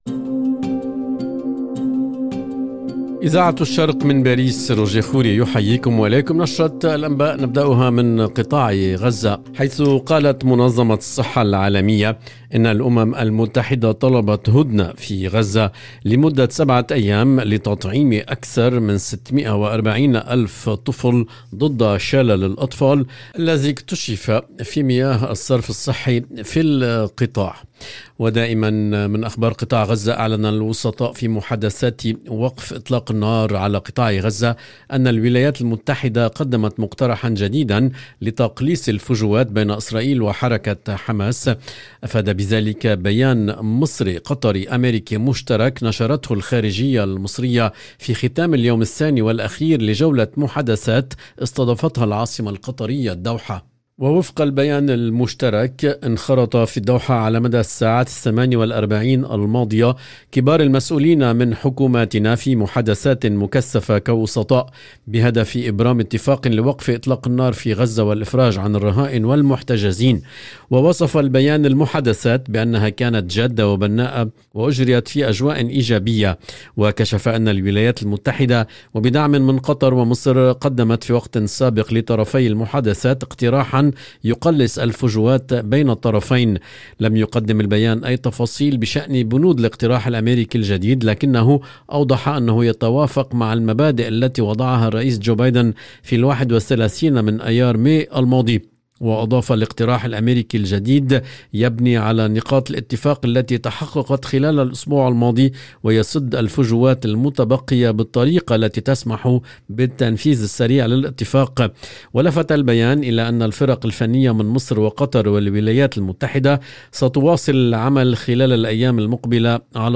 EDITION DU JOURNAL DU SOIR EN LANGUE ARABE DU 16/8/2024